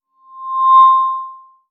Acople de megafonía
altavoz
megafonía
micrófono
Sonidos: Especiales